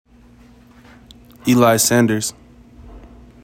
Pronunciation:  E lie SAND erss